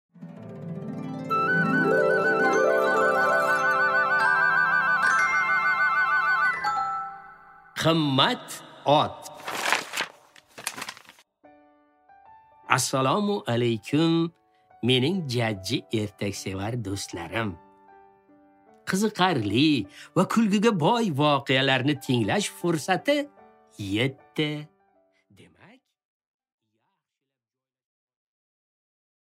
Аудиокнига Qimmat ot